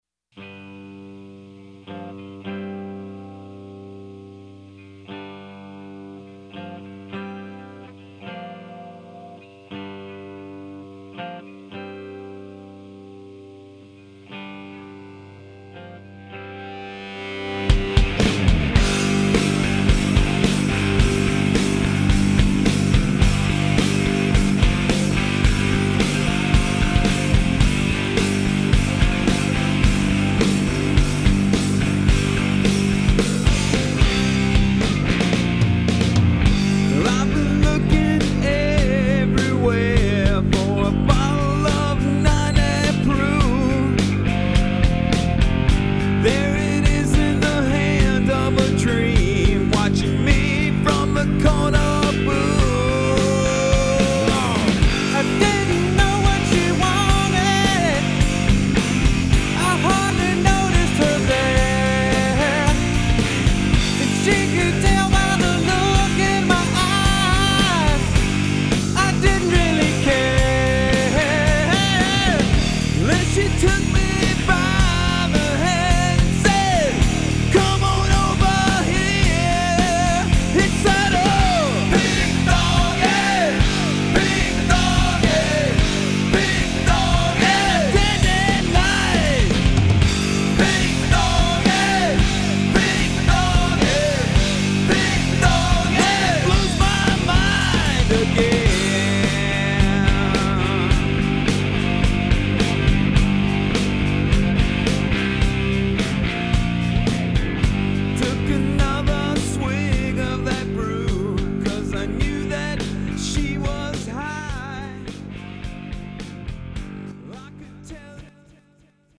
13 tracks of hard-rockin' mayhem!!!